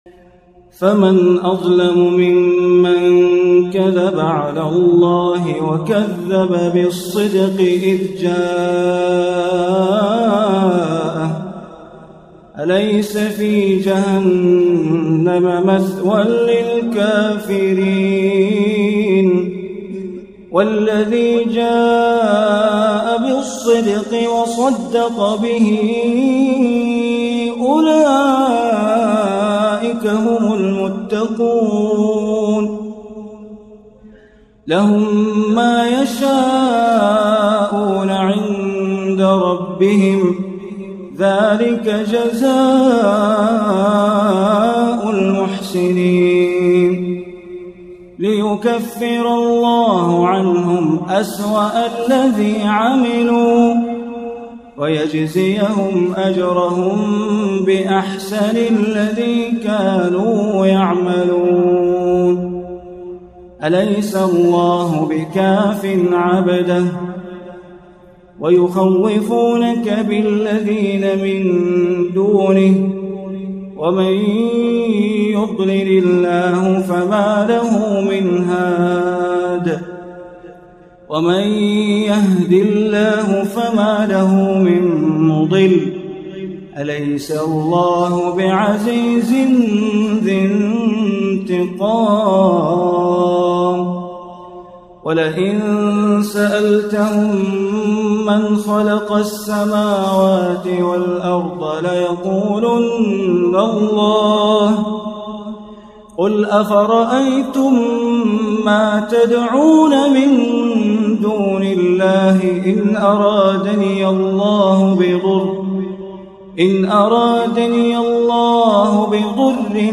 بندر بلیله - ترتیل جزء بیست و چهارم قرآن